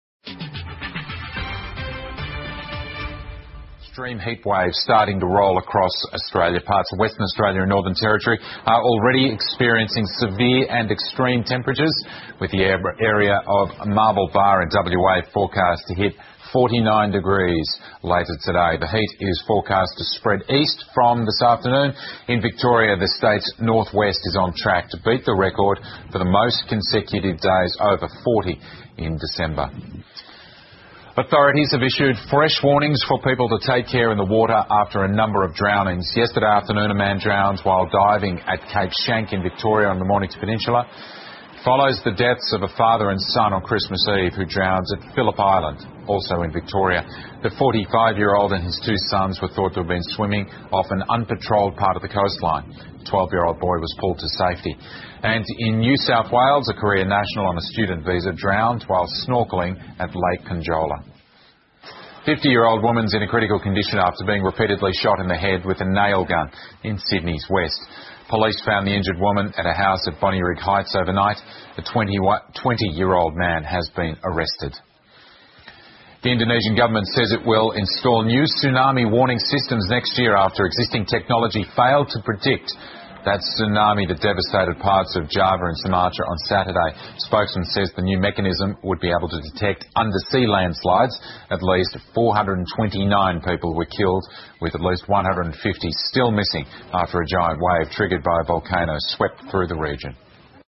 澳洲新闻 (ABC新闻快递) 极端热浪席卷澳大利亚 印尼将更换海啸预警系统 听力文件下载—在线英语听力室